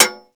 Index of /server/sound/vj_impact_metal/bullet_metal
metalsolid9.wav